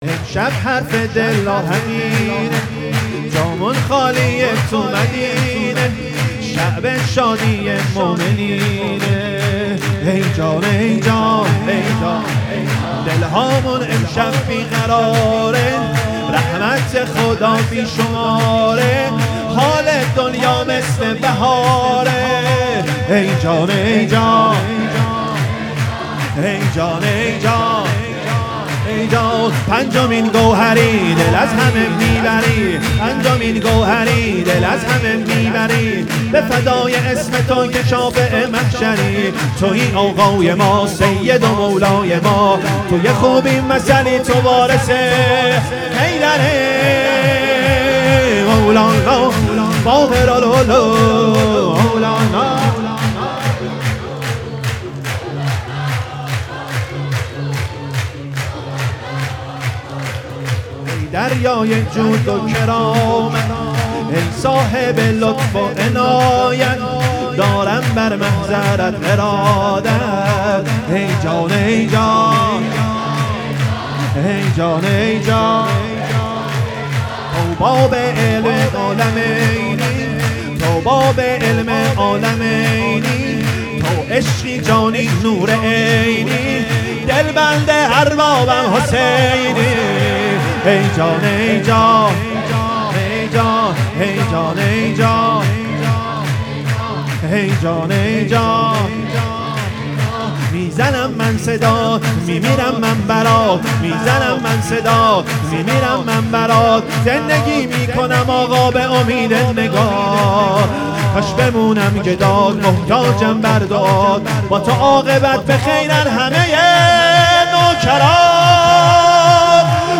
سرود
ولادت امام باقر(ع)